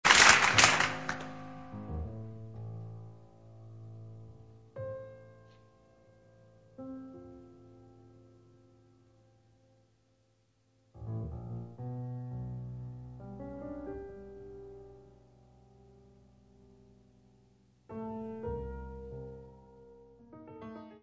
Área:  Novas Linguagens Musicais